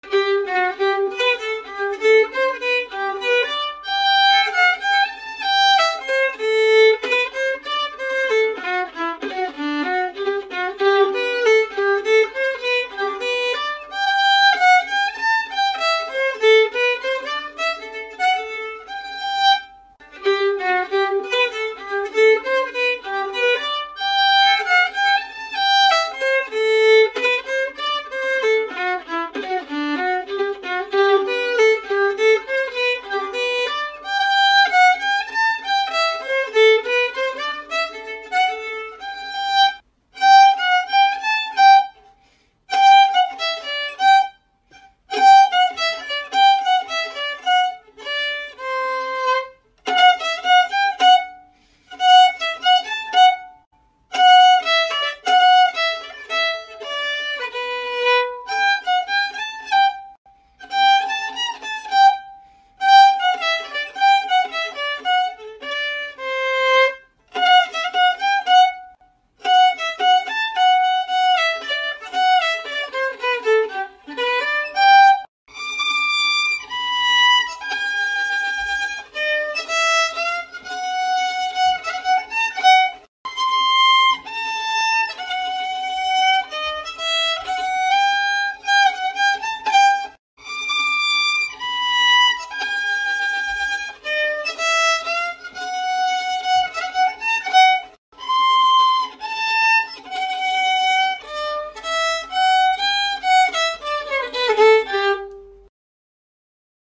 Key: G
Form: Two-Step
Played slowly for learning
Genre/Style: Quebecois
Ah-les-Femmes-slow-Final.mp3